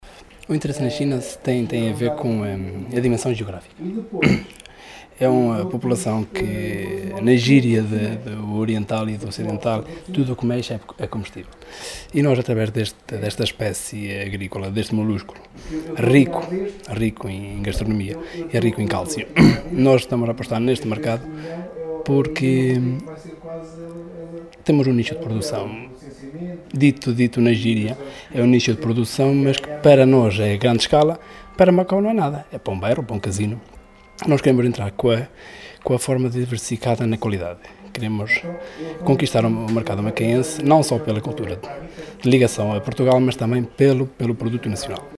Declarações à margem da sessão de esclarecimento “Macau como Plataforma dos 3 Centros – Apoio à exportação para o mercado chinês”, que ontem decorreu em Macedo de Cavaleiros.